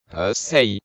swordman_ack1.wav